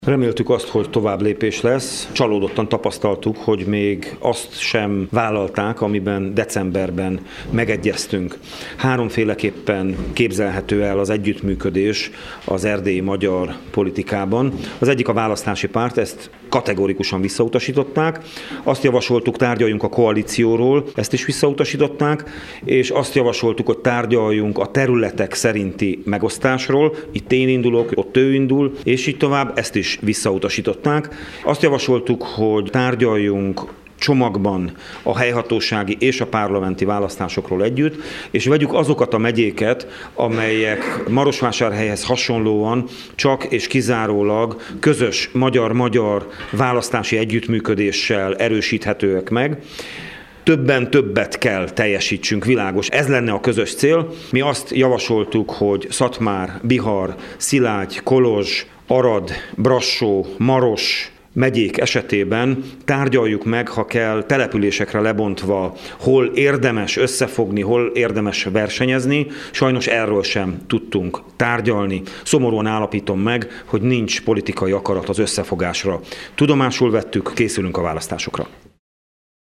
Az Erdélyi Magyar Néppárt küldöttsége csalódottan állapította meg, hogy nincs politikai akarat az összefogásra. Szilágyi Zsolt pártelnököt hallják.